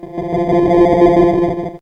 Electronic Sound
cartoon
Electronic